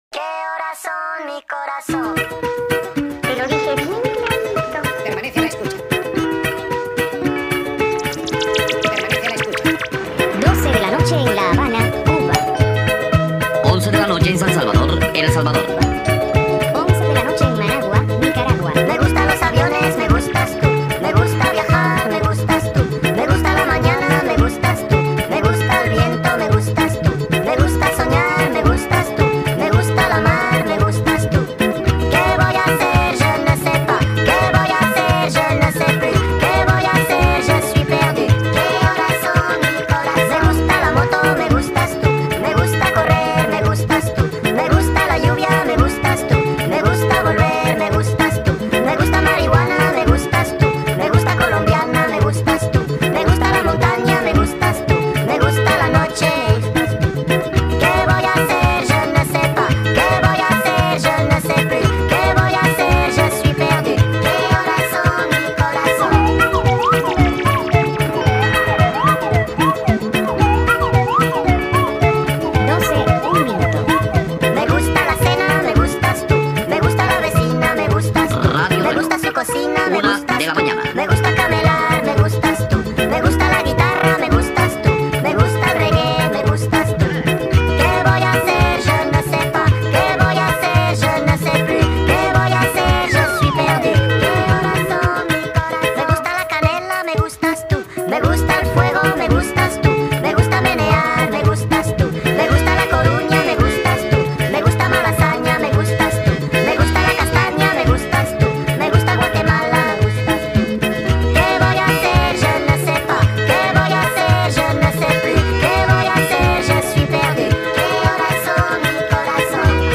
در نسخه Sped Up
عاشقانه خارجی